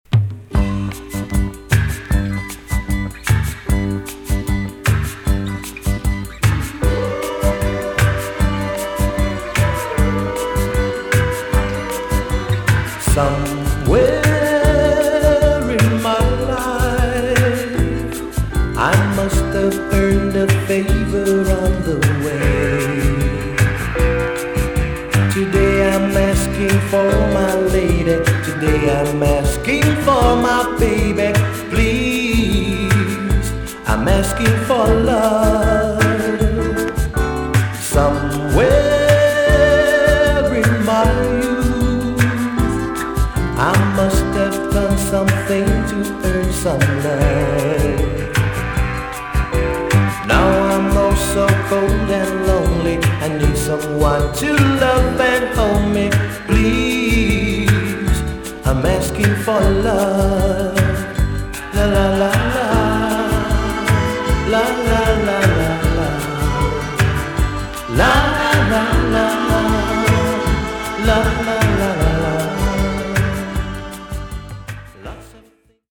TOP >JAMAICAN SOUL & etc
EX- 音はキレイです。
1994 , WICKED JAMAICAN SOUL TUNE!!